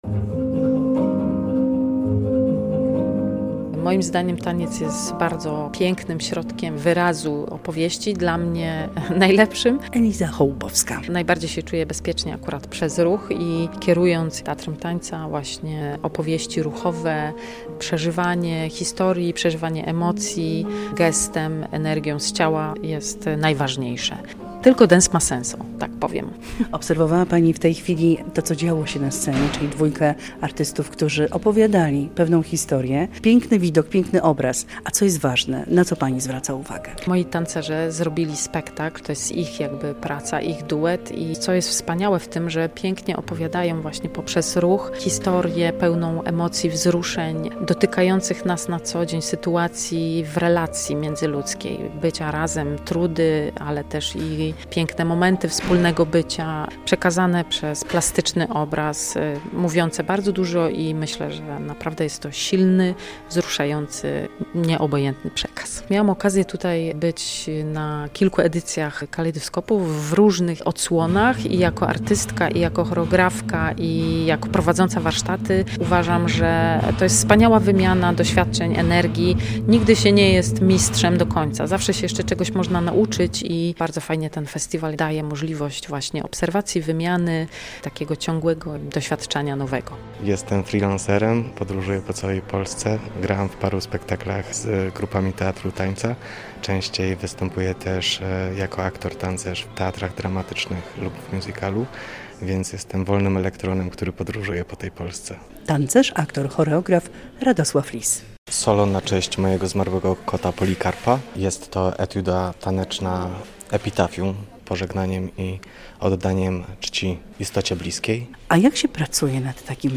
Festiwal tańca Kalejdoskop - relacja